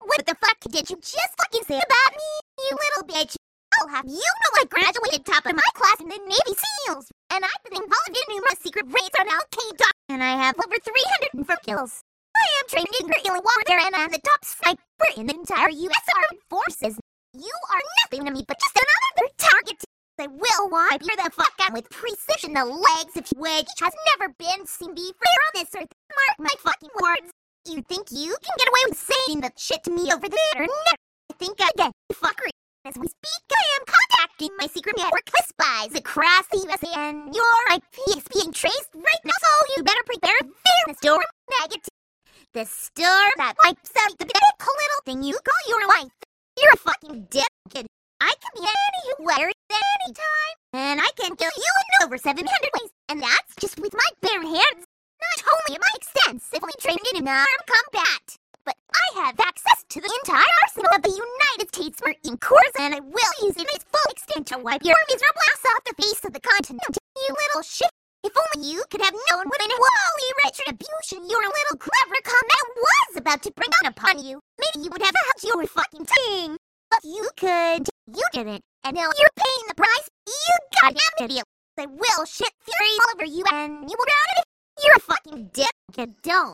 This exists! It's called "unit selection", and was an early method for generating high quality speech before everything became statistical.
But I still like this technique because it feels like a YTPMV shitpost (same technique, after all), but also has moments where it sounds more natural than any TTS because it uses the real samples.